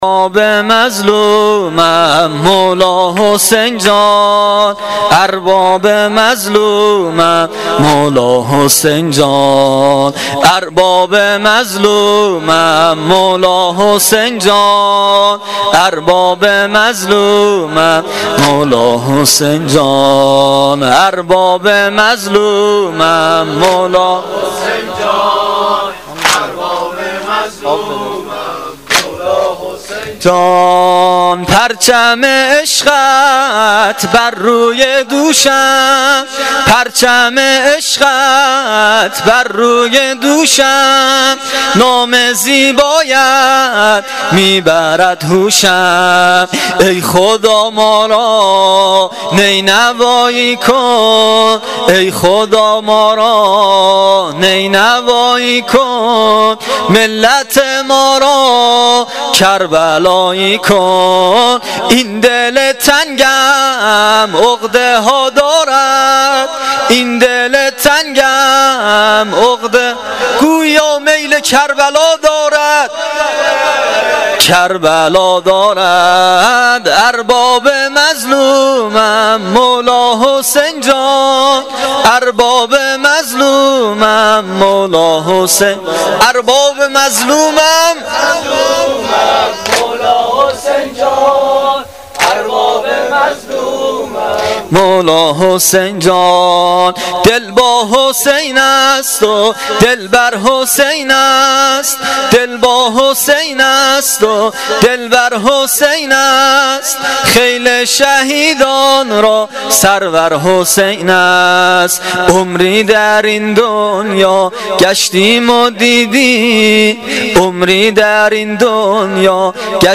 واحد تند شب چهارم محرم الحرام 1396